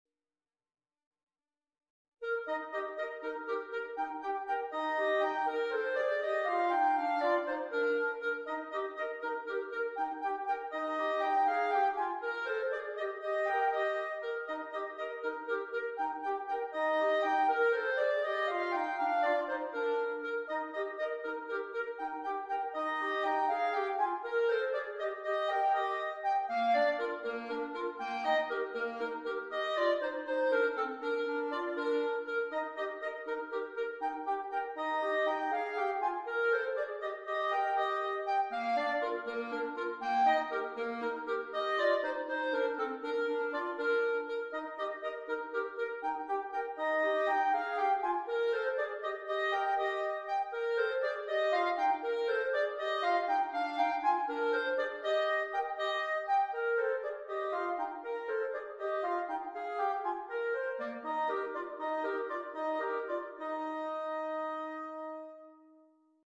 Per due clarinetti
10 facili duetti piacevoli.